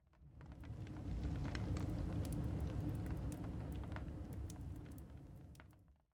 blastfurnace5.ogg